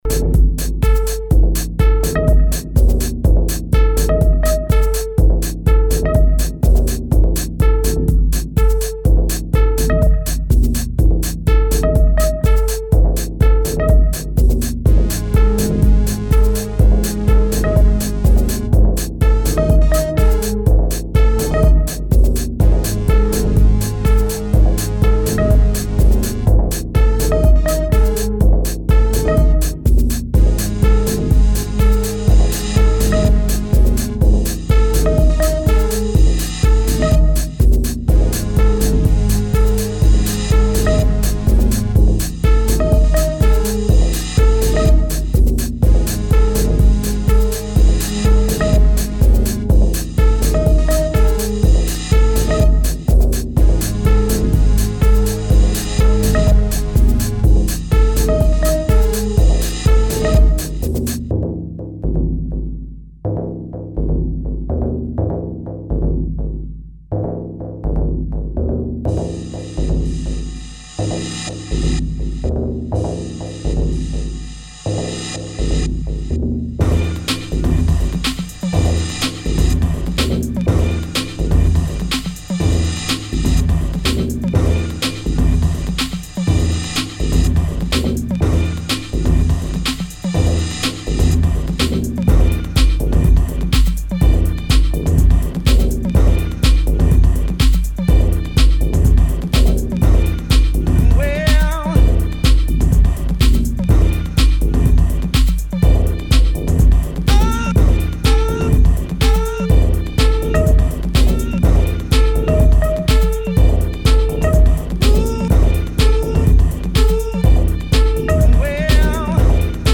[ TECHNO / DEEP HOUSE ]